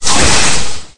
Thunder4.ogg